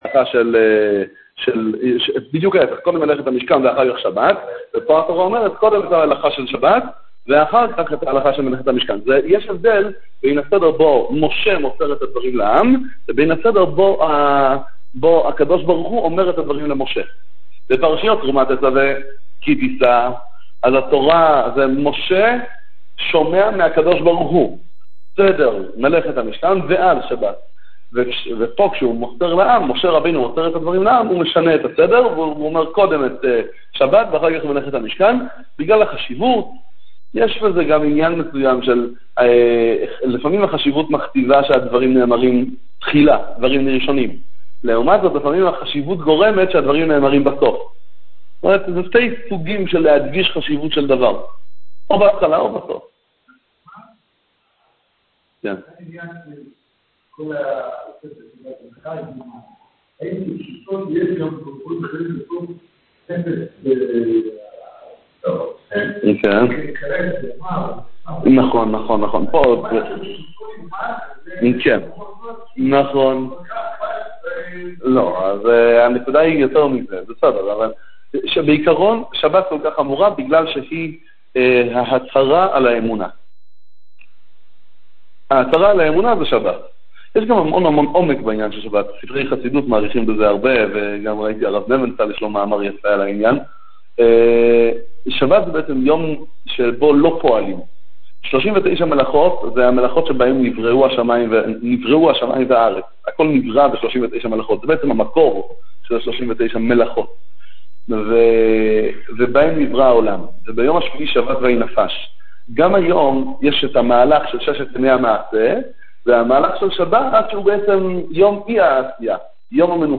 שיעור על פרשת ויקהל מביהמ"ד משאת מרדכי